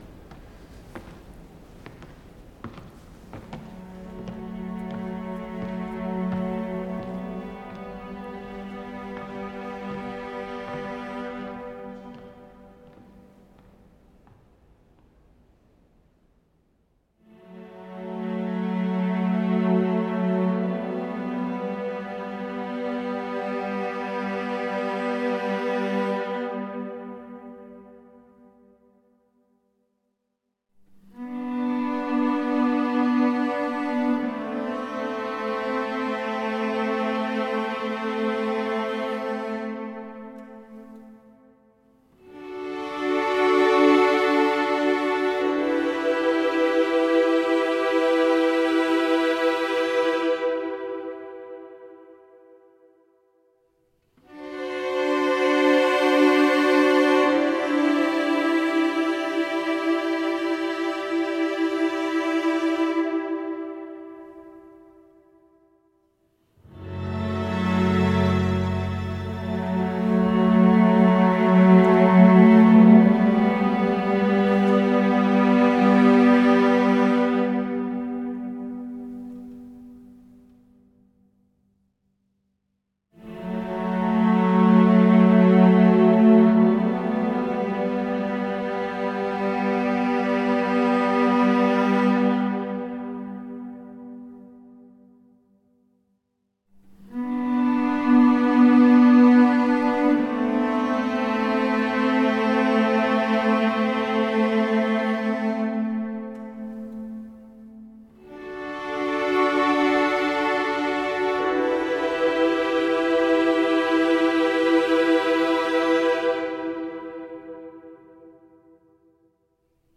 MP3 clip from this title's soundtrack